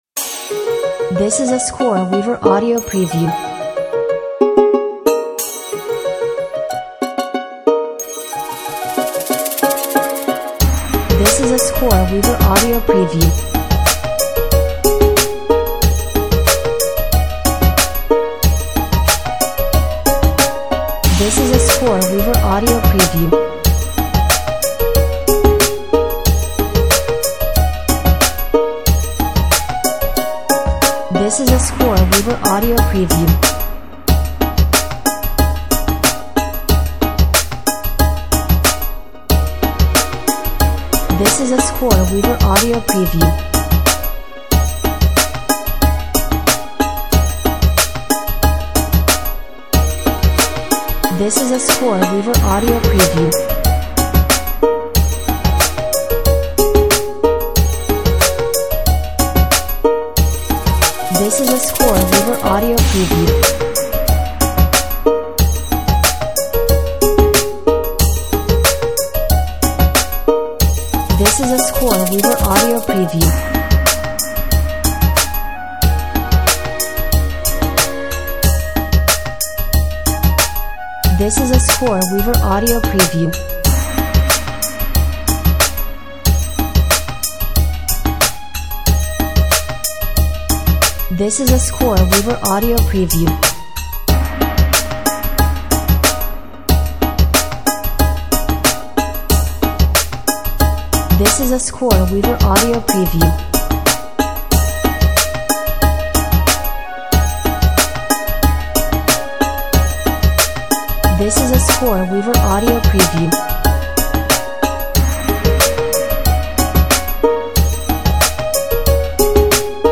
Enchanting, beautiful track with a positive vibe.